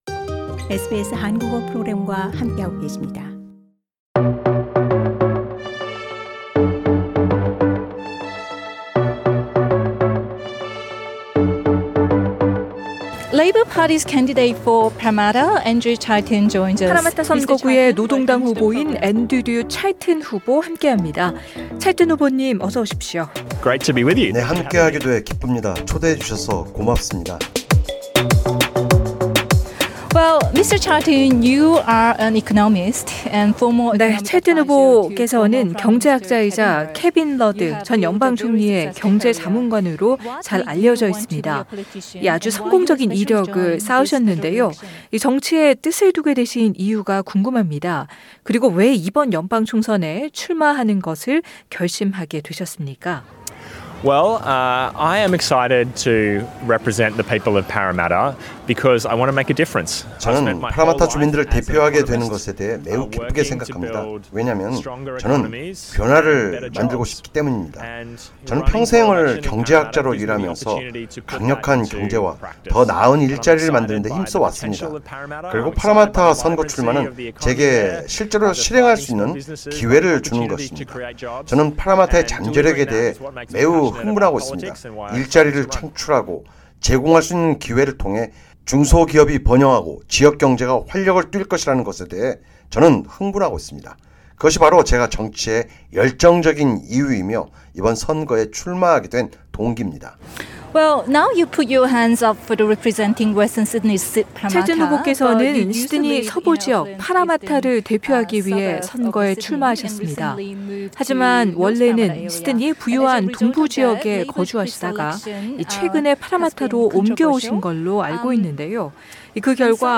Election Exchange라는 이름으로 저희 SBS 한국어 프로그램 뿐 아니라 북경어, 광둥어, 힌디어, 아랍어 프로그램이 현장에서 총선에 출마한 후보자들을 인터뷰하는 기회를 가진 겁니다.